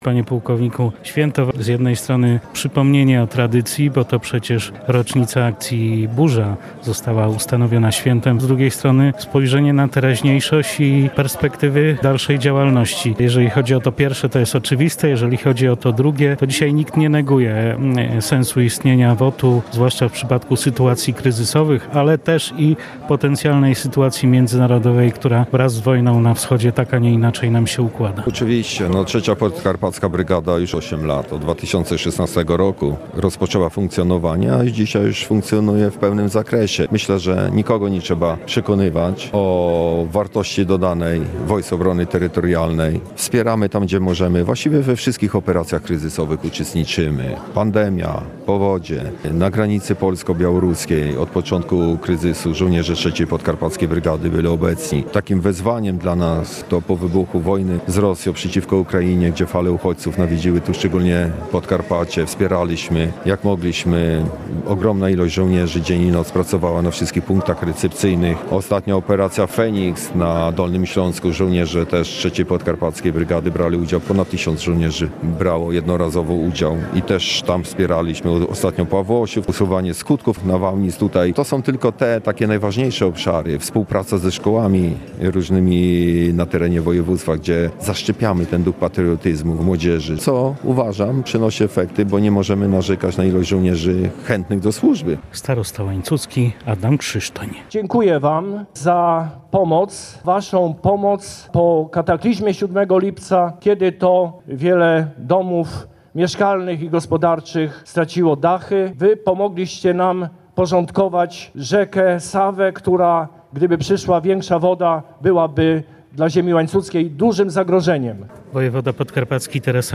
Uroczystym apelem w parku Muzeum-Zamku w Łańcucie żołnierze 3. Podkarpackiej Brygady Obrony Terytorialnej uczcili swoje święto.